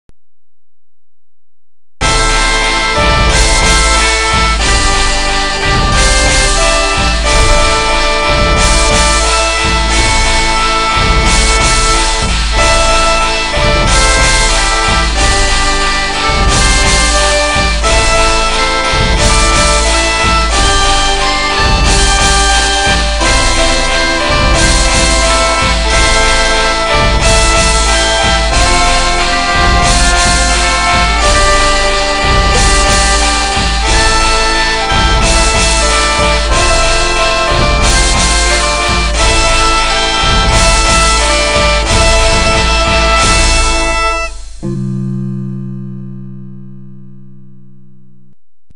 楼主怎么对清朝国歌感兴趣，是想复辟呀 清朝国歌实在不好听，我又给你找到一个演奏版的，演唱版的不好找了